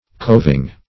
Coving \Cov"ing\, n. (Arch.)